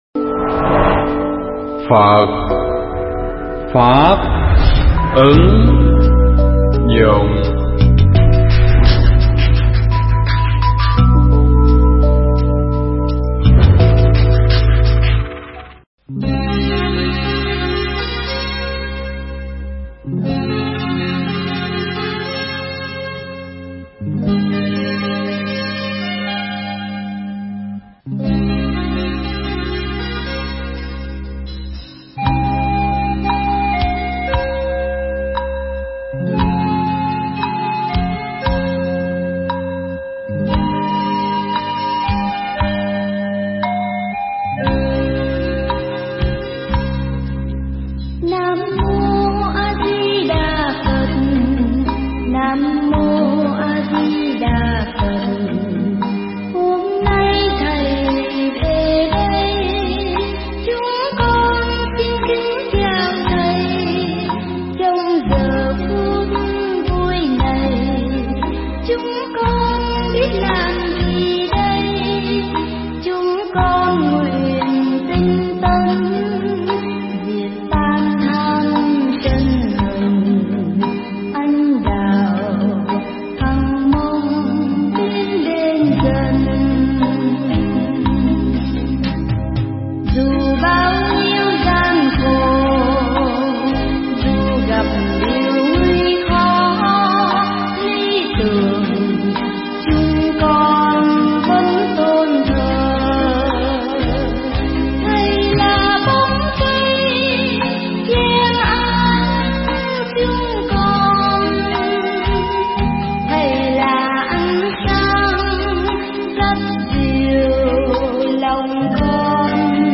Mp3 pháp thoại Phong Cách Người Phật Tử Đi Chùa